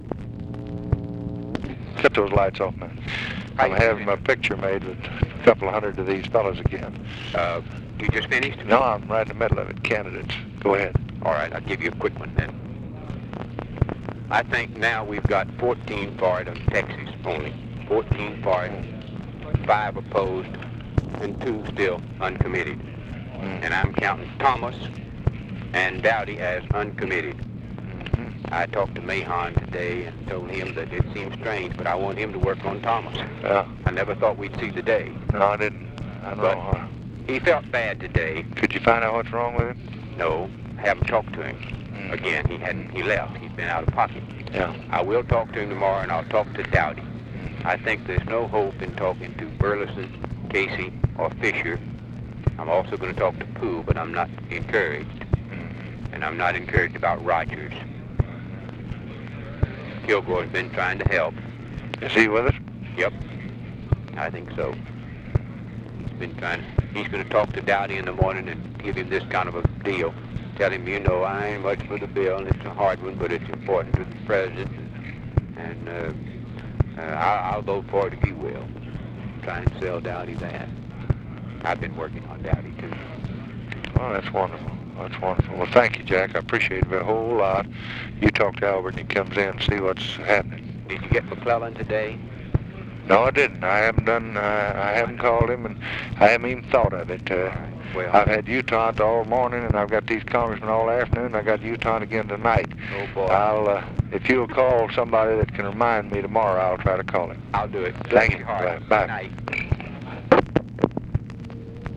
Conversation with JACK BROOKS, August 6, 1964